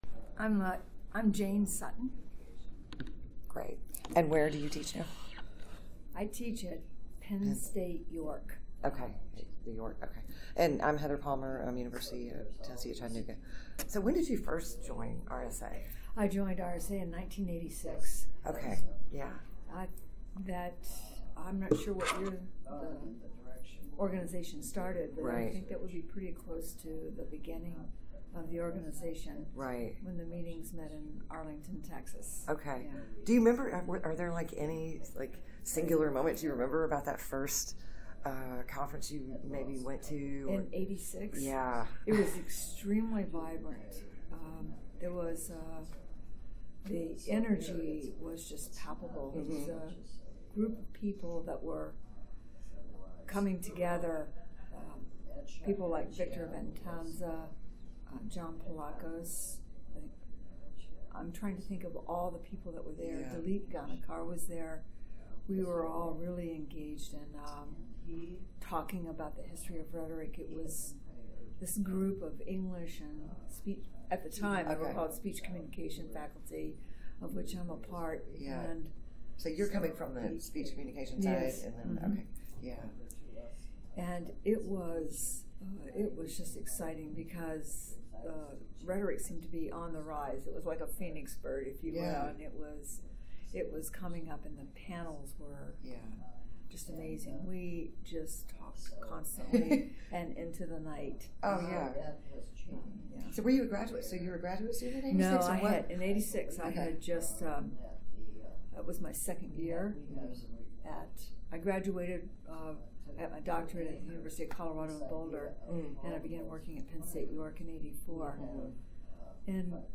Oral History
Location 2018 RSA Conference in Minneapolis, Minnesota